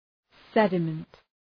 Προφορά
{‘sedəmənt}